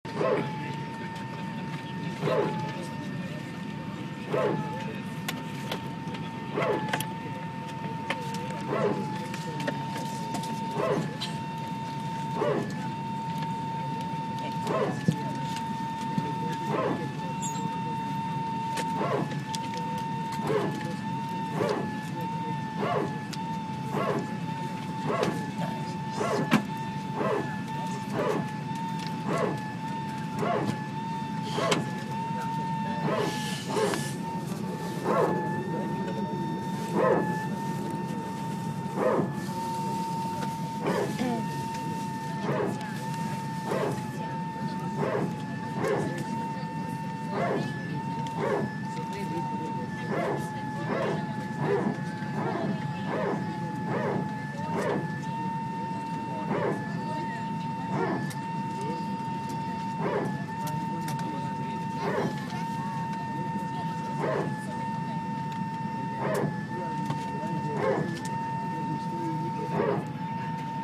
Wir waren gerade vom Gate zurückgestossen worden, Triebwerke gestartet, und dann einige wenige Meter gerollt.
Bald setzte der typische PTU (?)-Klang ein - bloss, dass dieser nicht wie üblich nur kurz währte, sondern mehr als zwei Minuten.
Das "Bellen" ist die PTU, das "Heulen" ist die Gelbe Elektrische Hydraulikpumpe.